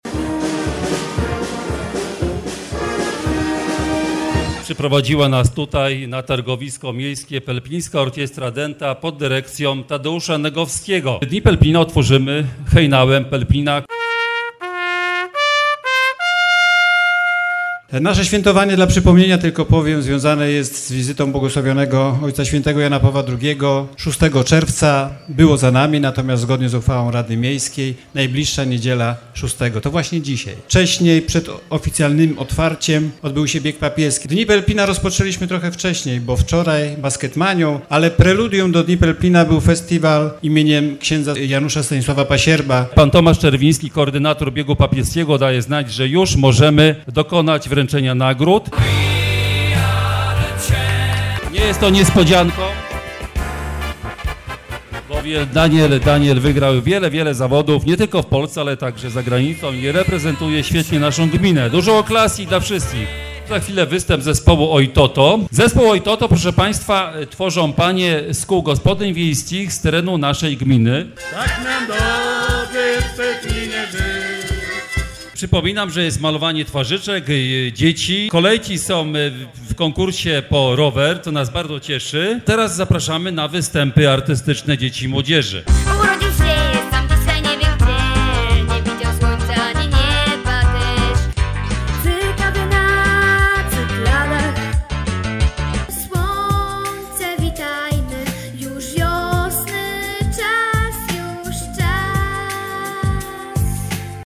Relacja Radia Głos z pierwszej części sobotnich obchodów (2,443 kB)